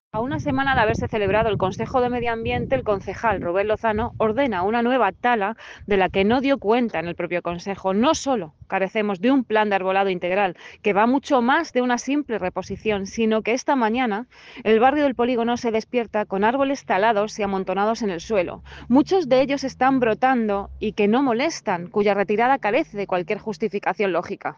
AUDIO-PSOE-Laura-Villacanas-sobre-talas-en-Poligono.mp3